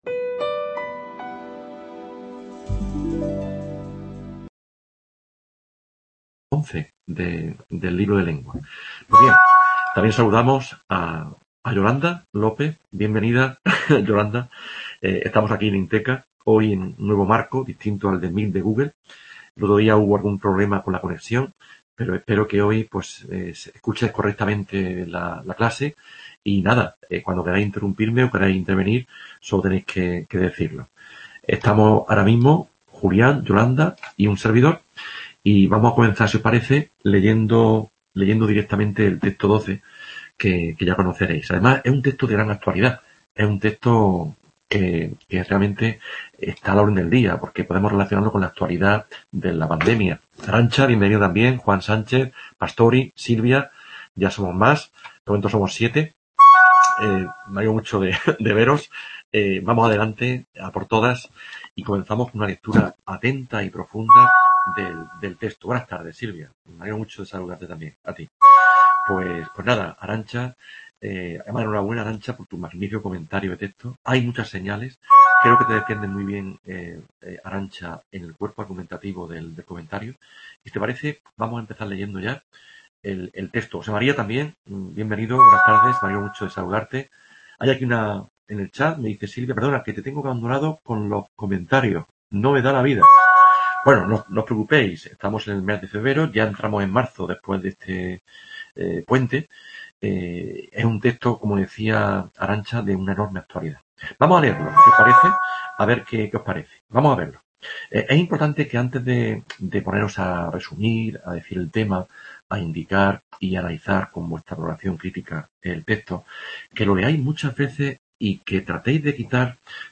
EL COMPLEMENTO PREDICATIVO Description En esta webconferencia se comenta el texto 12 ("Vacuna difícil"), se corrigen los ejercicios del tema 10 y se explica, asimismo, el tema 11 dedicado a la oración simple (atributo y predicativo).